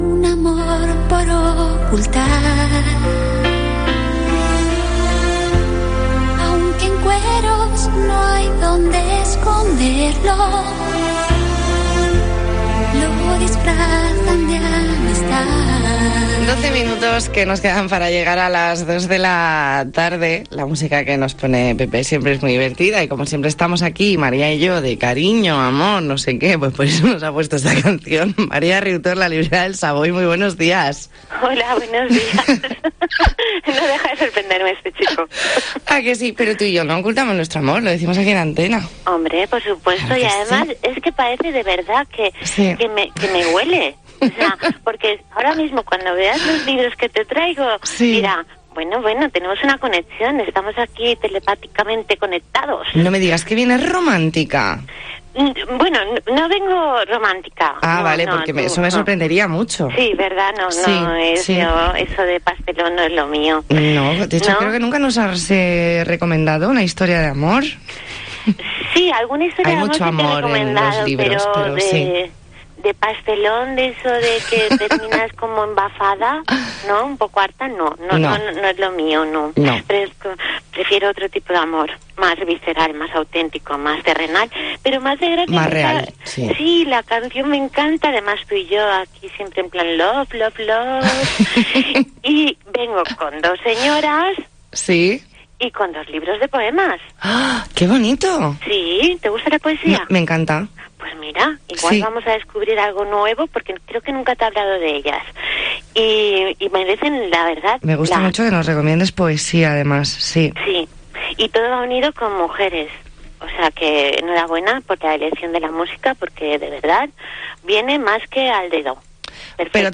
Entrevista en 'La Mañana en COPE Más Mallorca', martes 16 de junio de 2020.